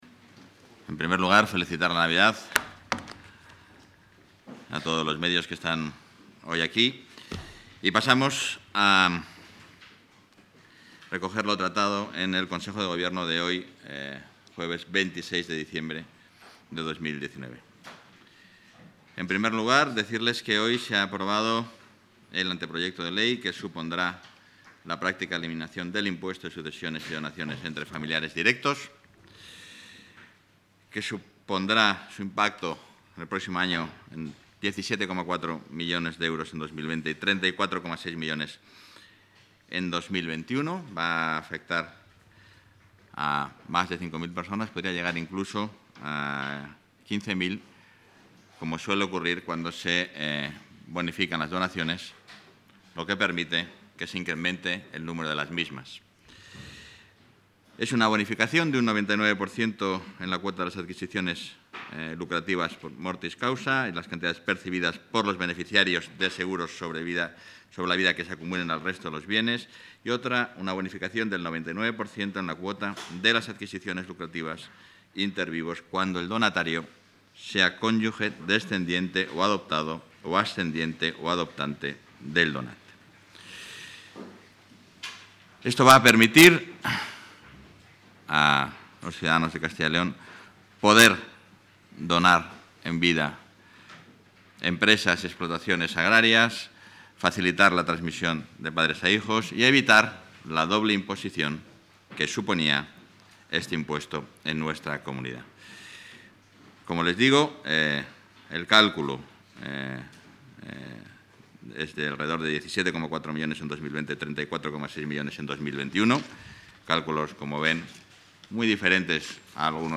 Audio rueda de prensa.
Consejo de Gobierno del 26 de diciembre de 2019.